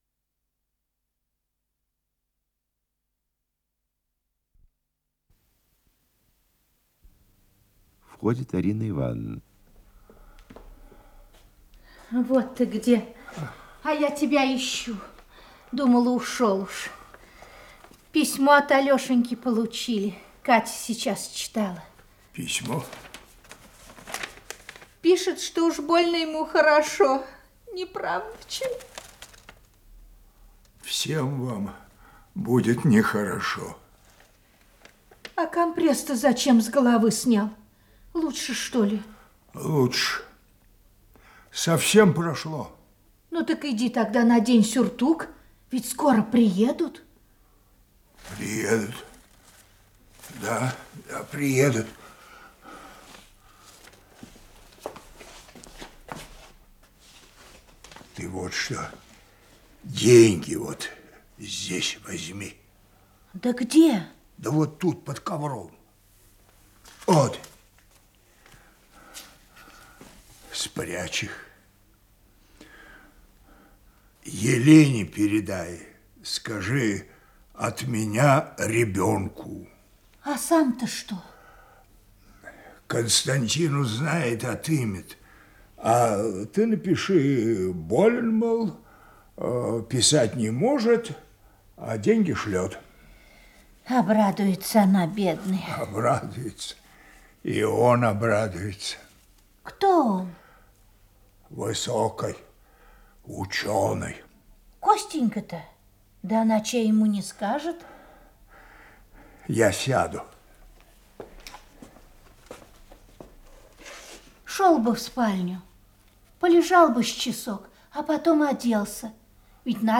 Исполнитель: Артисты Государственного академического Малого театра СССР
Радиокомпозиция спектакля ГАМТа СССР